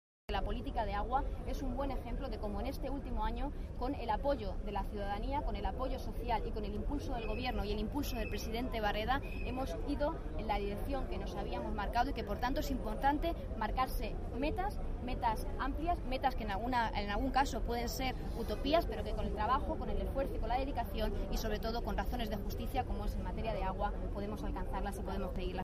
La portavoz del Gobierno de Castilla-La Mancha, Isabel Rodríguez, a preguntas de los periodistas durante la presentación en Puertollano (Ciudad Real) de la campaña itinerante del PSCM-PSOE ‘Sí a Castilla-La Mancha’, aseguró que en materia de agua la política del Ejecutivo castellano-manchego “ha marcado un 3-0” al trasvase Tajo-Segura.
Cortes de audio de la rueda de prensa